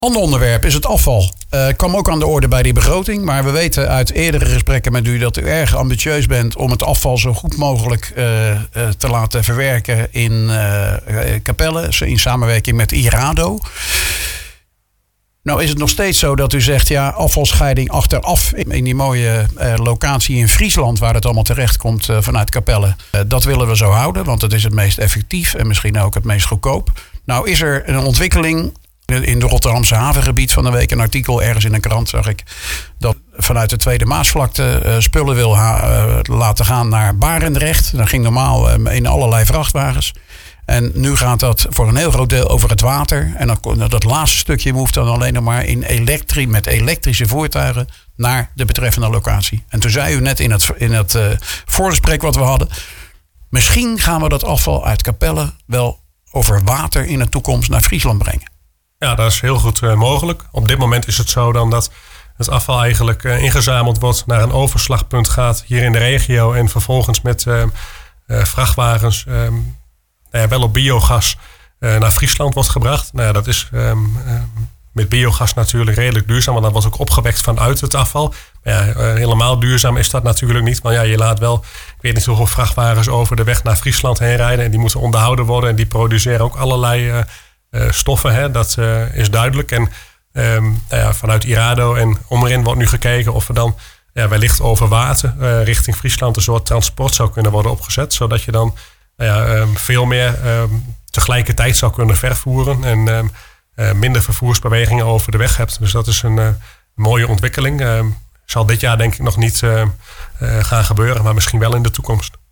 Wethouder Sjoerd Geissler was 27 november te gast in IJssel-nieuws. Op het onderwerp afval kwam aan de orde een eventuele andere manier van vervoer van afval.